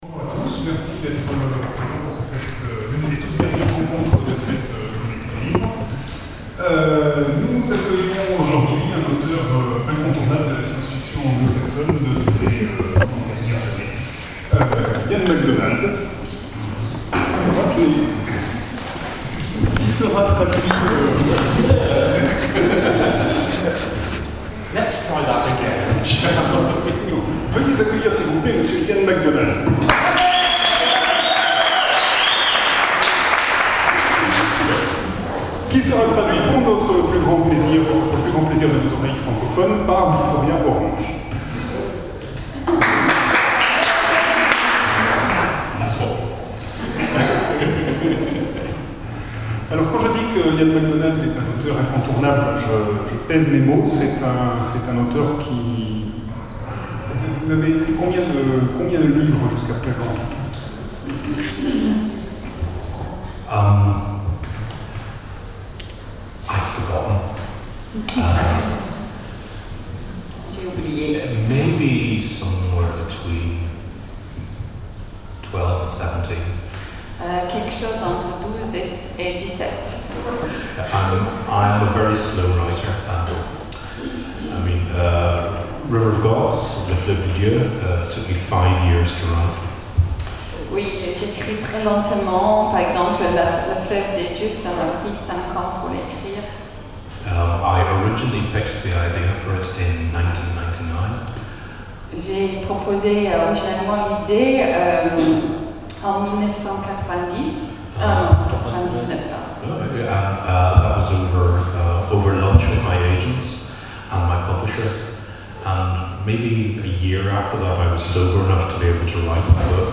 Comédie du livre 2012 : Rencontre avec Ian McDonald
Rencontre avec un auteur Conférence